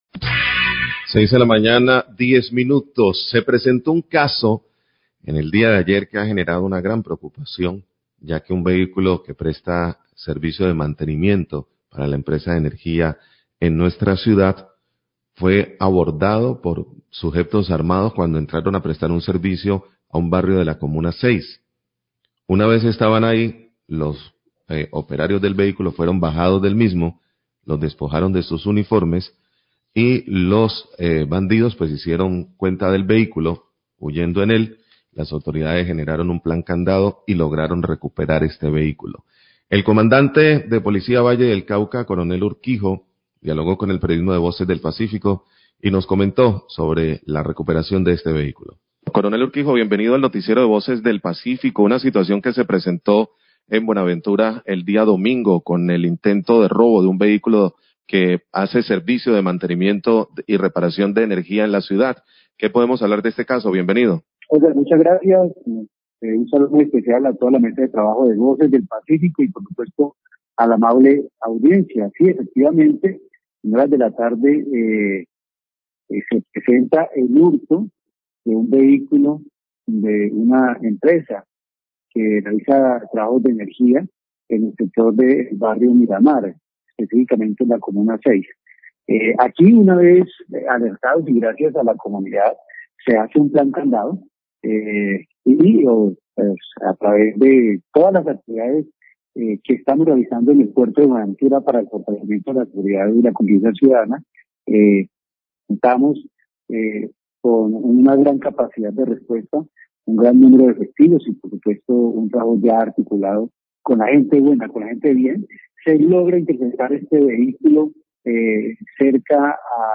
Radio
Pero luego las autoridades policiales recuperaron el vehículo. Declaraciones del Comandante de la Policía Valle, coronel Urquijo.